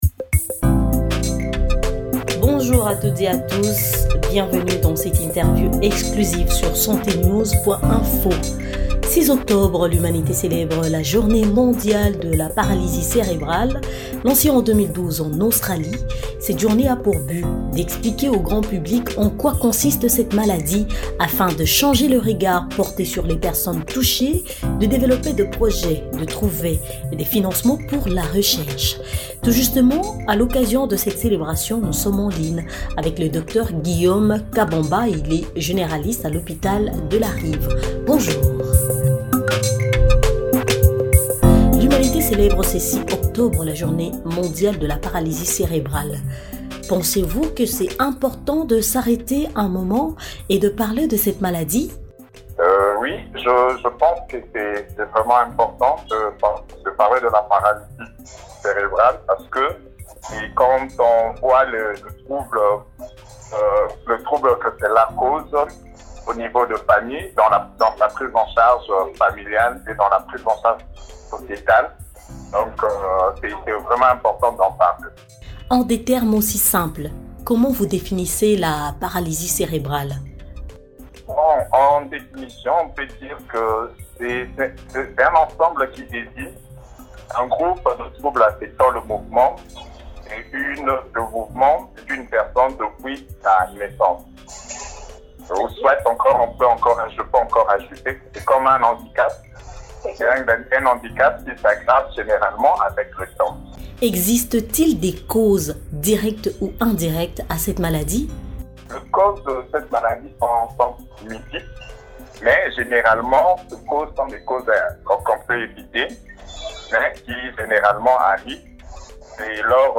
interviewé